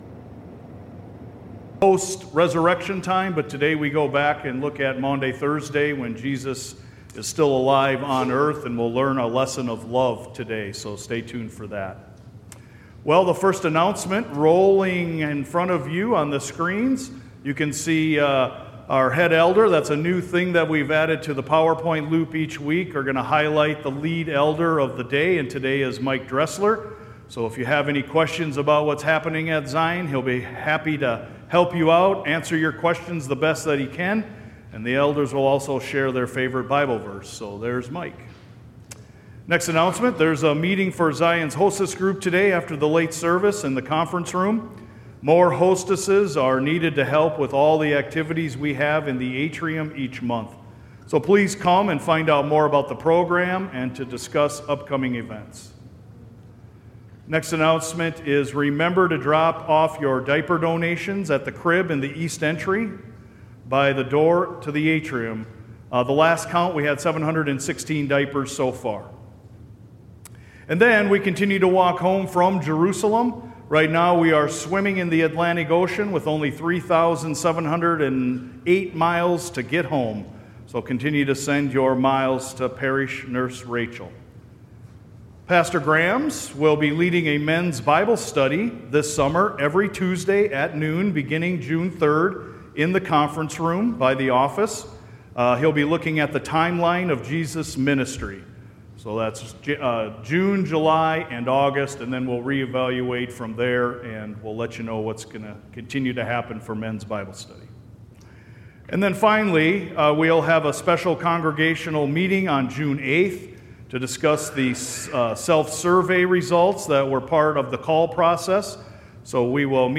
SERMON-518.mp3